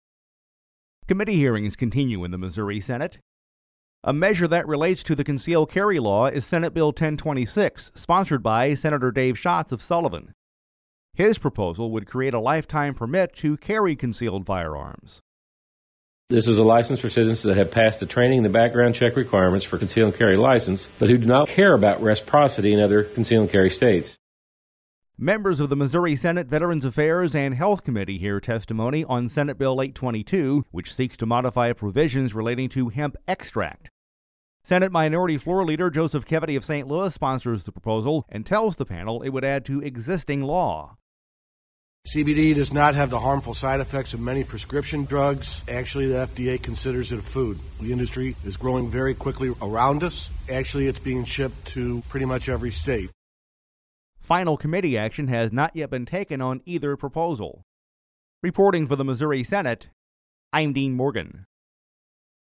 JEFFERSON CITY — Senate Bill 1026, which would create a lifetime permit to carry concealed firearms, and Senate Bill 822, which seeks to modify provisions relating to hemp extract, are heard in separate Missouri Senate committees. We’ve included actualities from Sen. Dave Schatz, R-Sullivan, and Senate Minority Floor Leader Joseph Keaveny, D-St. Louis, in this feature report